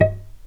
healing-soundscapes/Sound Banks/HSS_OP_Pack/Strings/cello/pizz/vc_pz-D#5-pp.AIF at bf8b0d83acd083cad68aa8590bc4568aa0baec05
vc_pz-D#5-pp.AIF